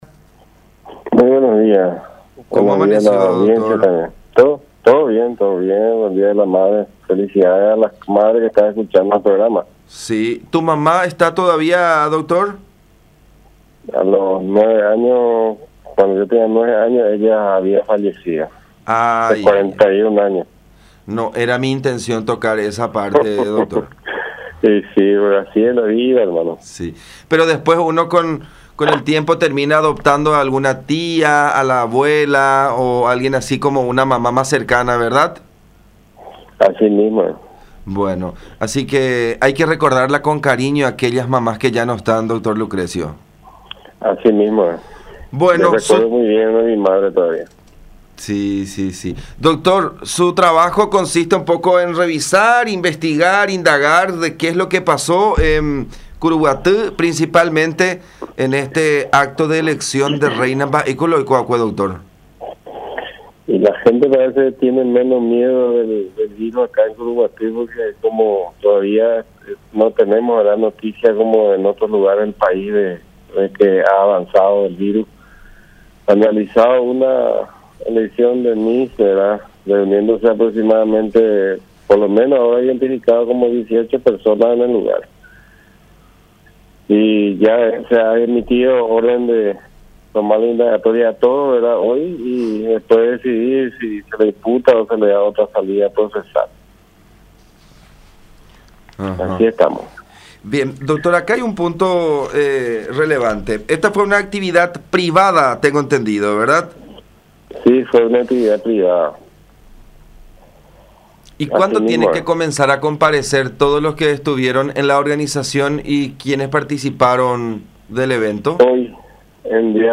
“Estoy esperando un informe oficial del intendente, porque las radios locales lo mencionaron a él por haber auspiciado el evento”, dijo el fiscal Cabrera en diálogo con La Unión en referencia a Nelson Martínez (ANR, Colorado Añetete), jefe comunal local.